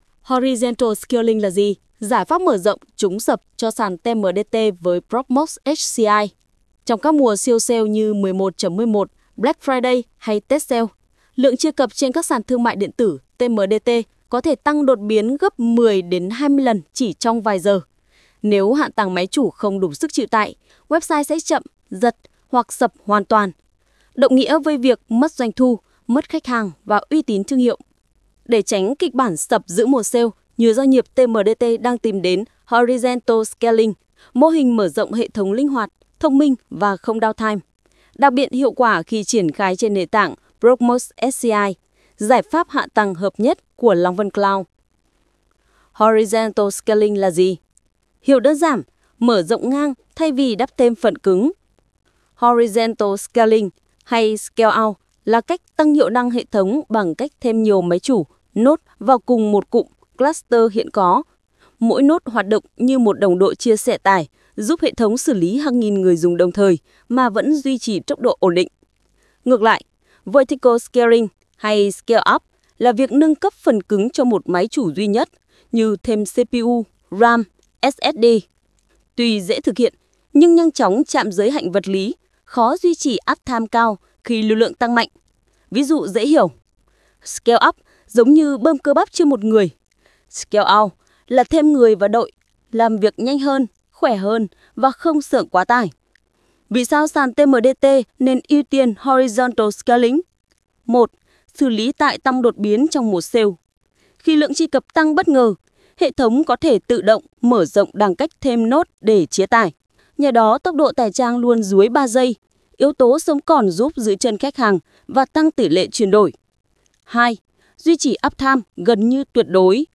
Âm thanh bài viết